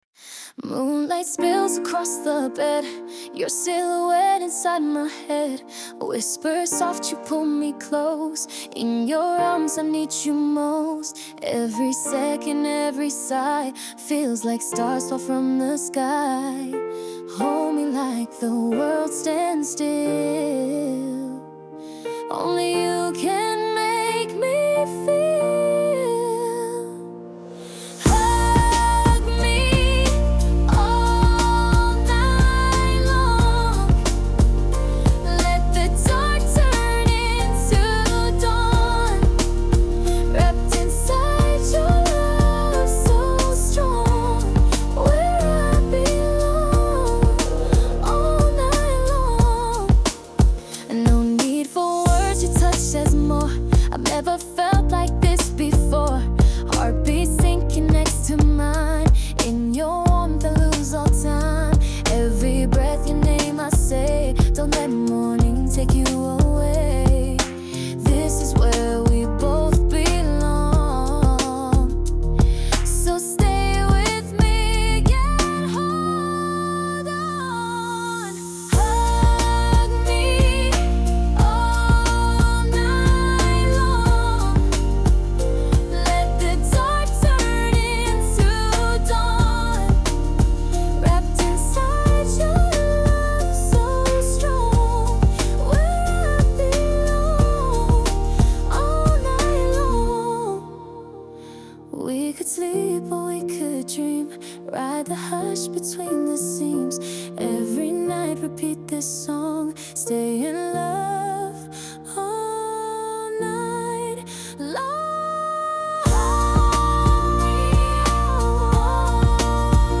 Genre:                   R&B/Soul (Dance)